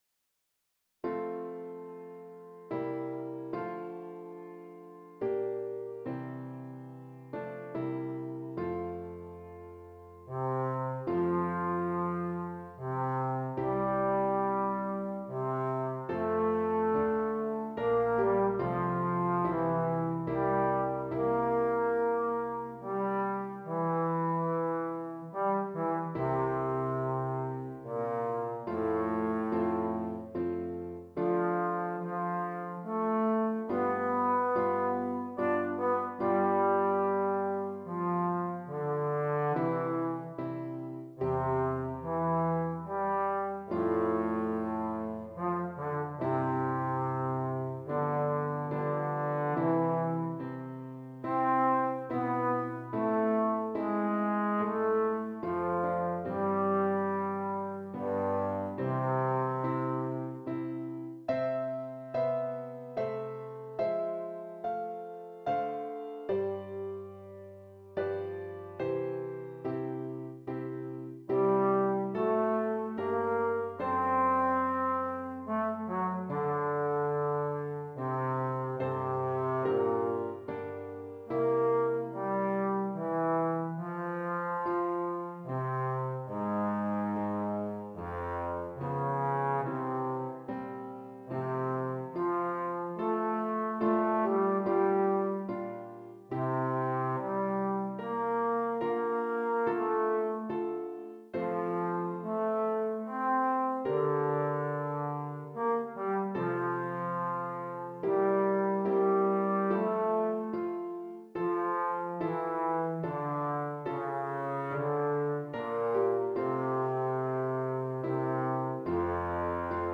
Trombone and Piano